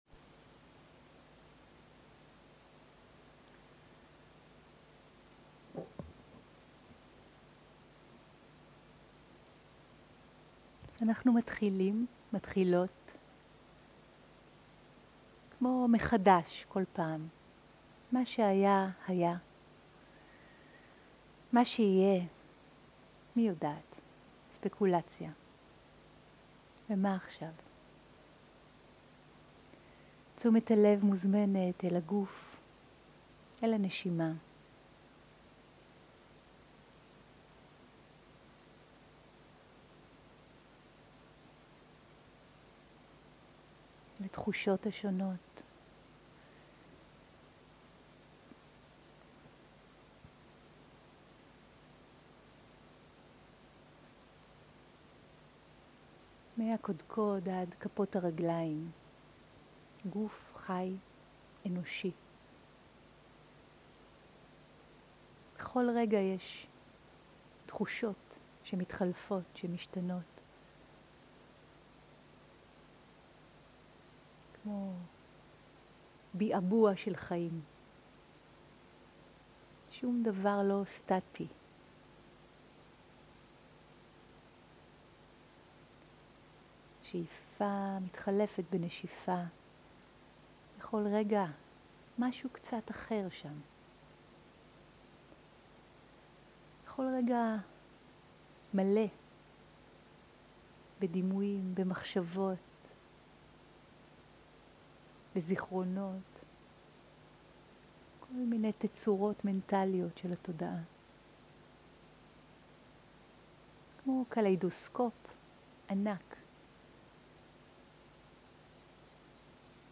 סוג ההקלטה: שיחות דהרמה
עברית איכות ההקלטה: איכות גבוהה מידע נוסף אודות ההקלטה